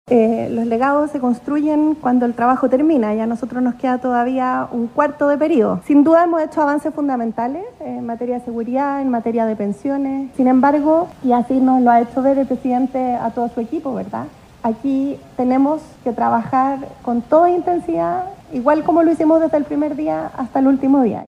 Así lo planteó la vocera subrogante, Aisén Etcheverry, quien además refrendó la idea de que este “es un Gobierno que ha estado comprometido en hacer reformas que son estructurales”.